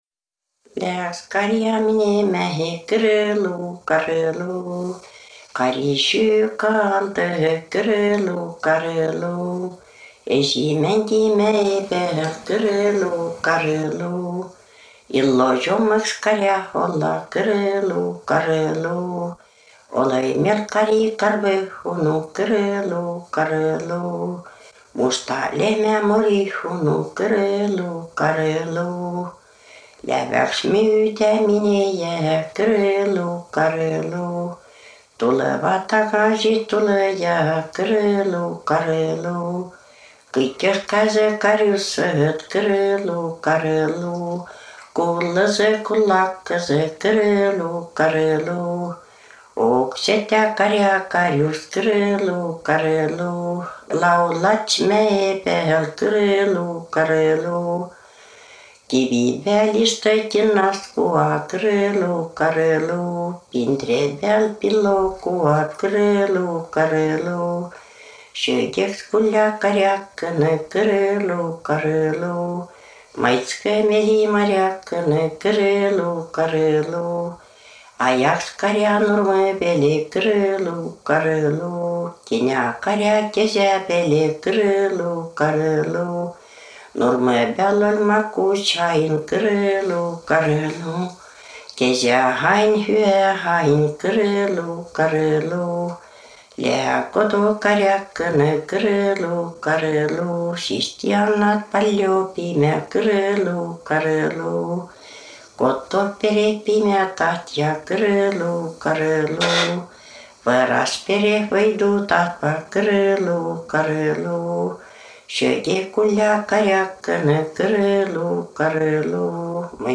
Kar´alaul: “Lää õks karja minemähe”
Muusiga